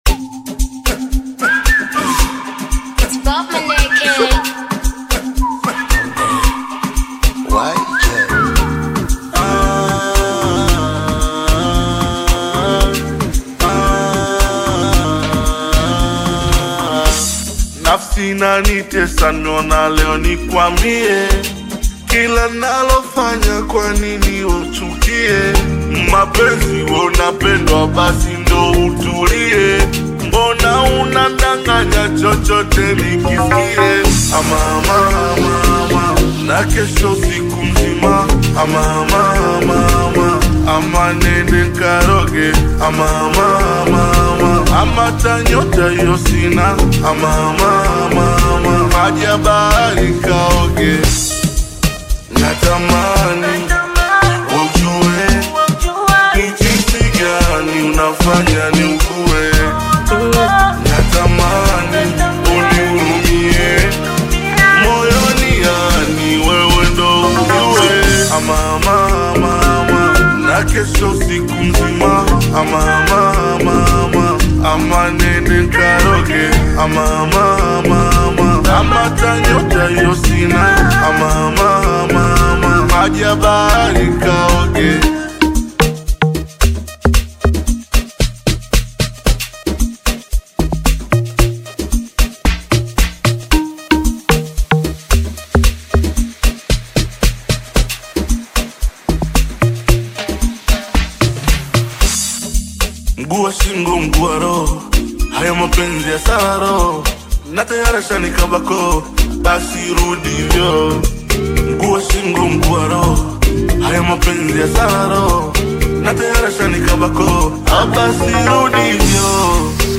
Bongo Flava music
Bongo Amapiano song